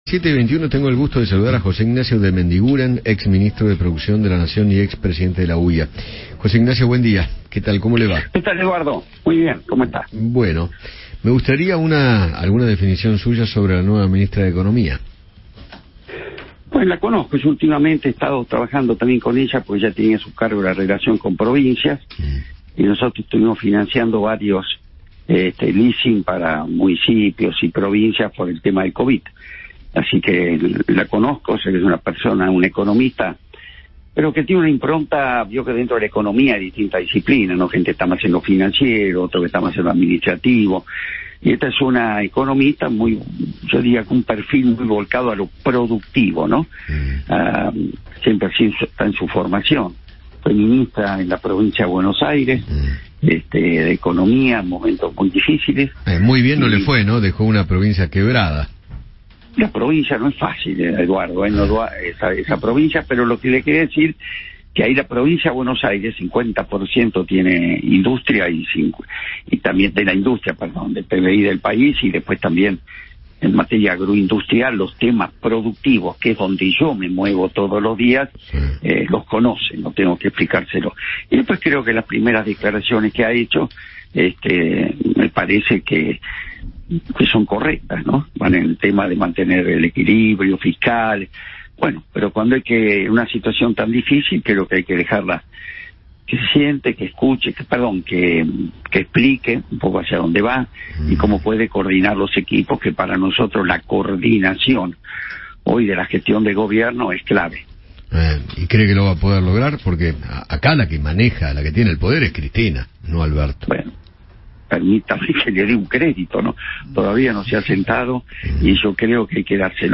José Ignacio de Mendiguren, ex ministro de Producción de la Nación y ex presidente de la UIA, habló con Eduardo Feinmann sobre la desiganción de Silvina Batakis y describió el perfil de la economista.